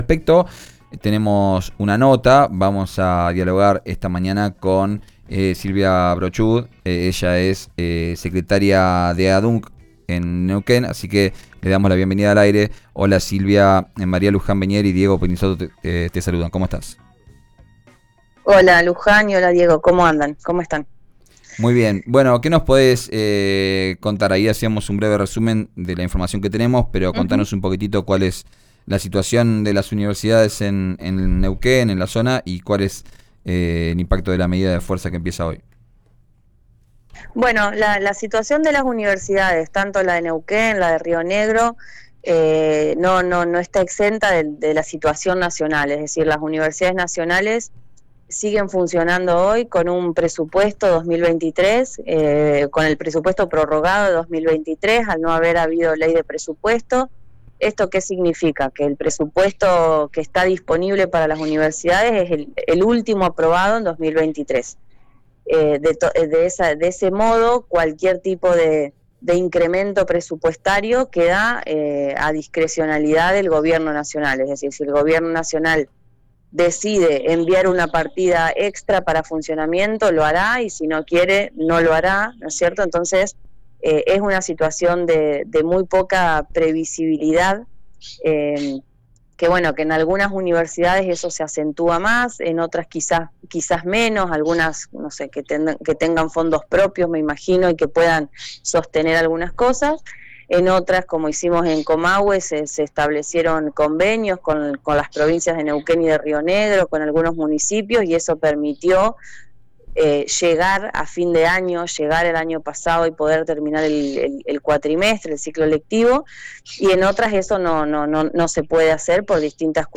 En dialogo con RÍO NEGRO RADIO